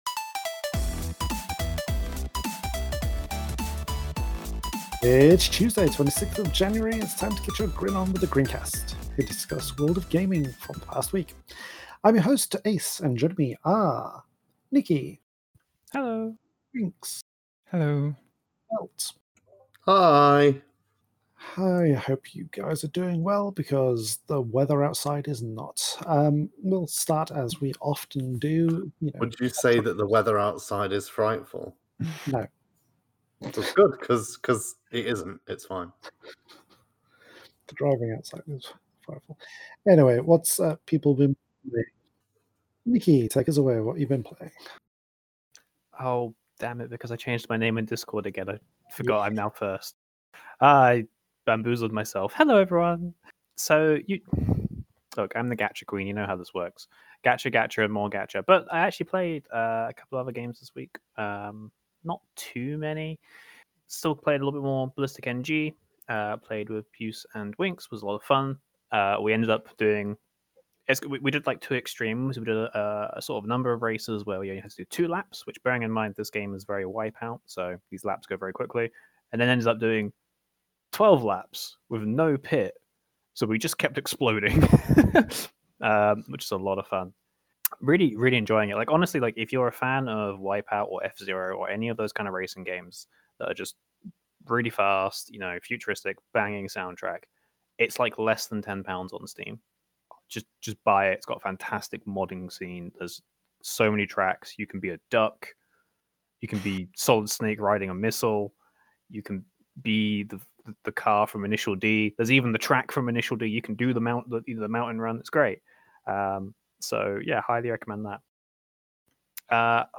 It's a bit shorter this week due to some recording issues, not that you can tell!